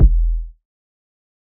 Kicks
TUPAC MURDER CONFESSION KICK.wav